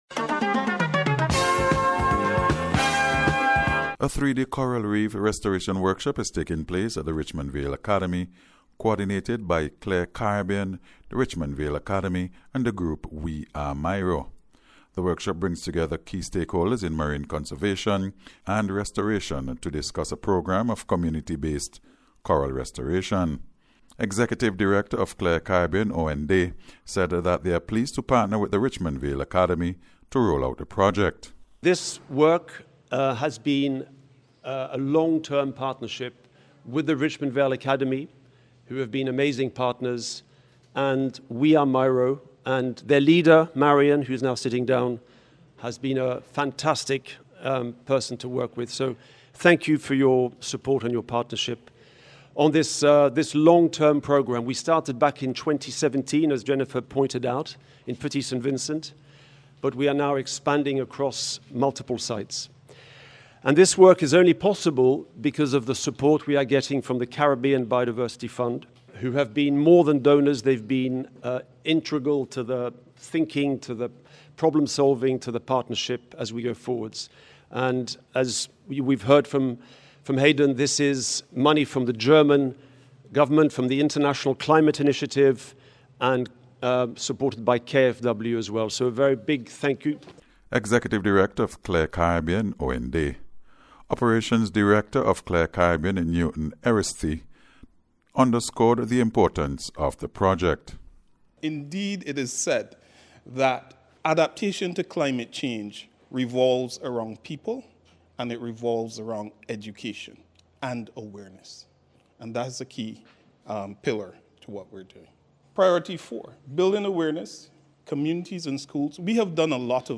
CORAL-REEF-RESTORATION-REPORT.mp3